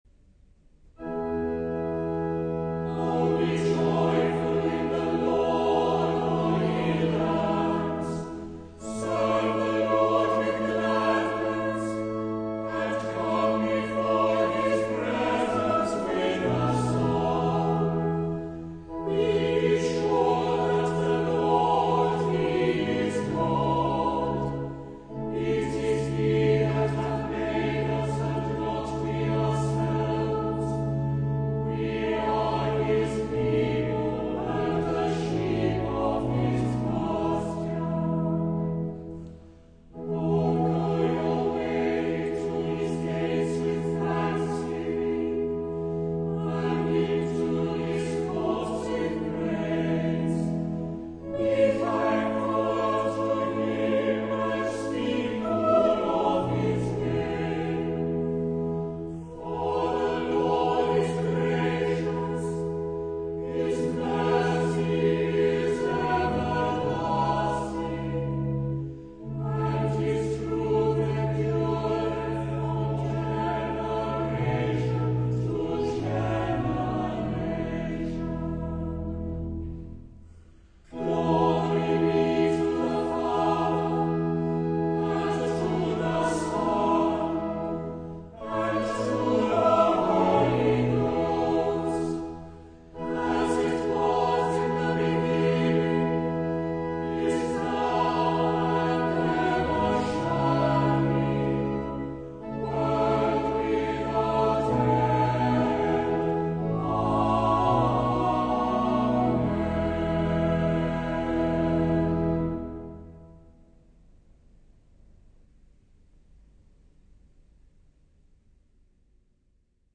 There is nothing to compare with the beauty and reverence of the Psalms sung in the Anglican chant.
20-jubilate-deo-chant_-havergal.mp3